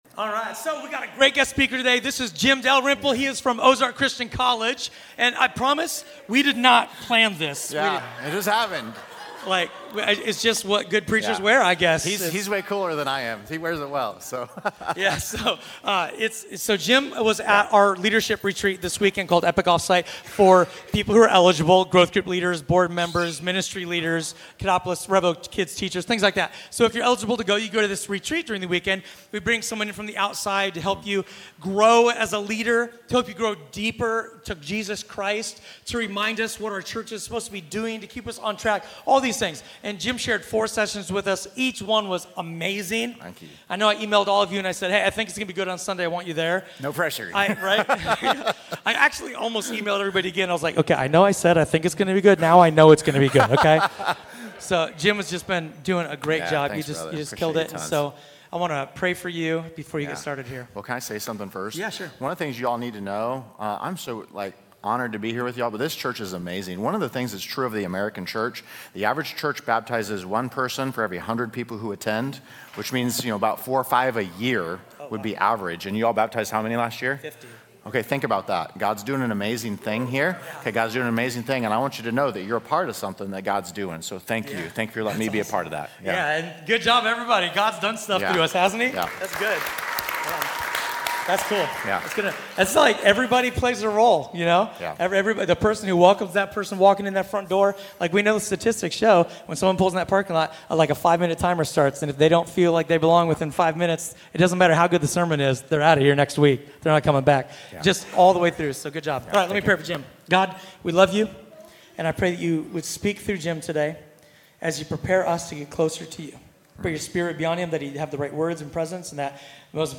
A sermon from the series "Guest."